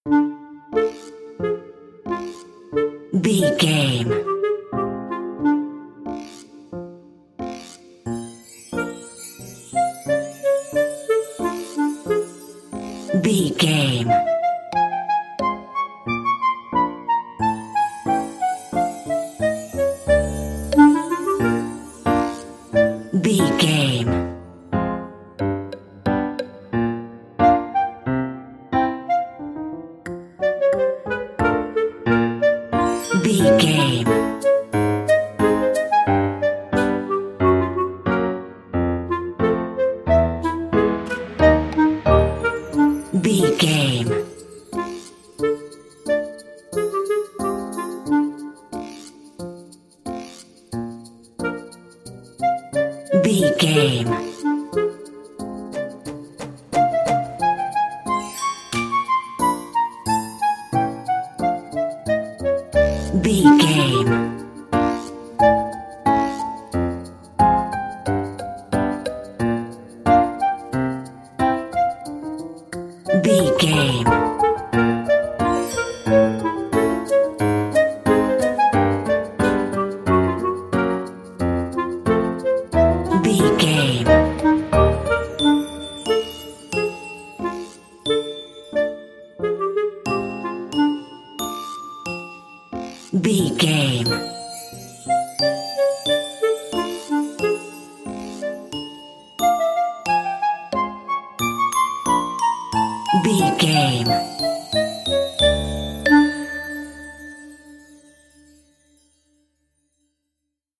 Uplifting
Aeolian/Minor
Slow
flute
oboe
piano
percussion
silly
circus
goofy
comical
cheerful
perky
Light hearted
quirky